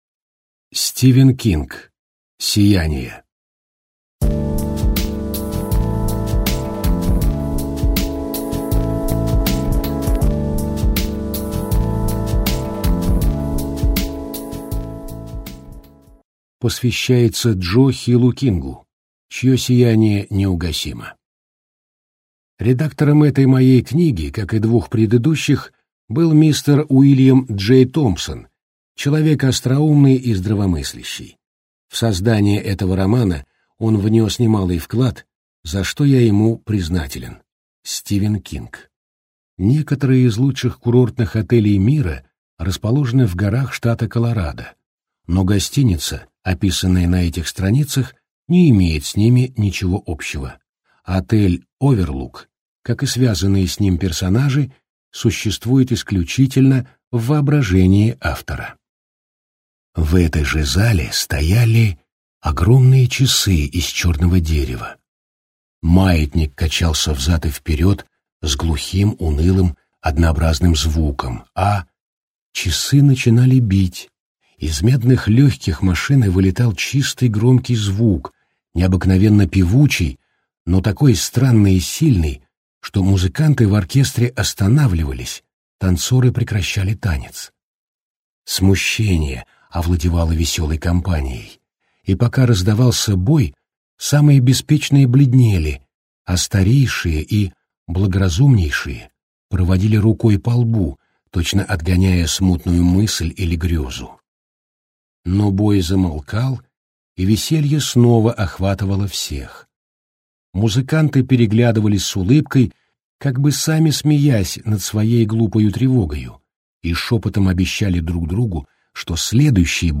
Аудиокнига Сияние - купить, скачать и слушать онлайн | КнигоПоиск